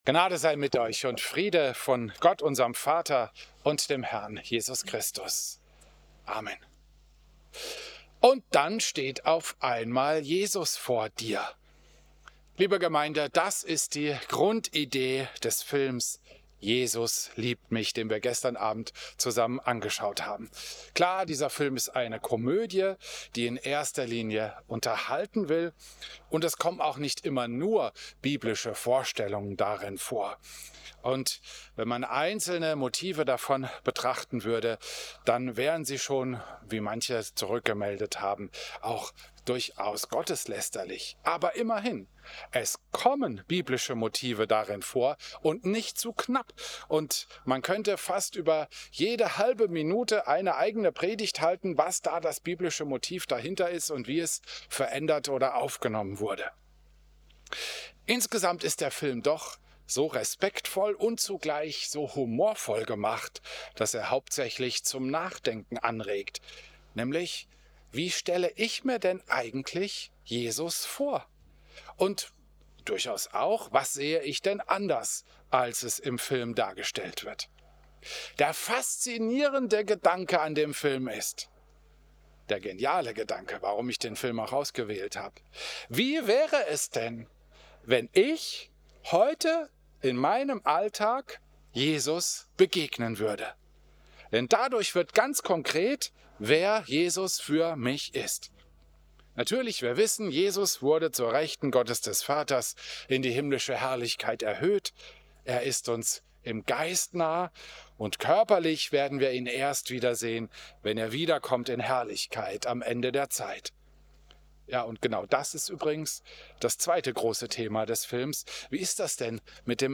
Predigt
Christus-Pavillon Volkenroda, 10. August 2025